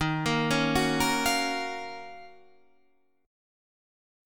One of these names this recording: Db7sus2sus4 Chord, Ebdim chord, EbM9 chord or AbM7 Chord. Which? EbM9 chord